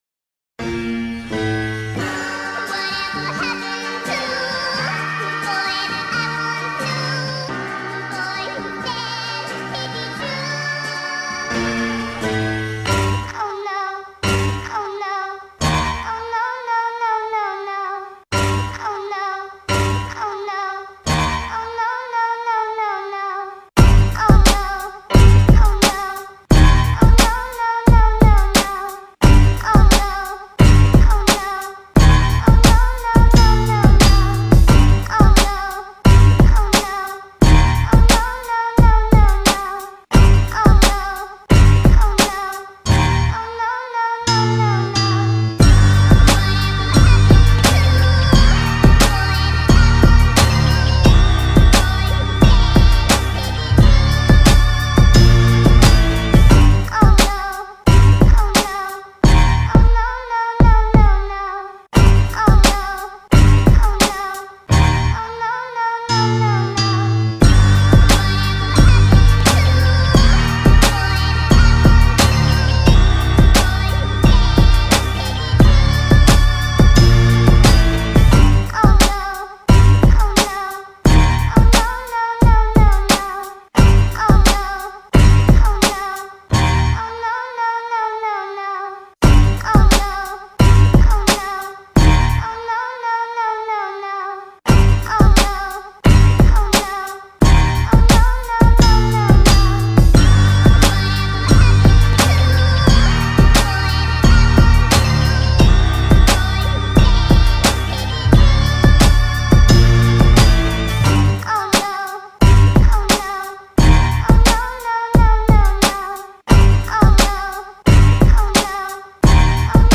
This is the official instrumental
Rap Instrumentals